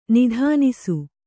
♪ nidhānisu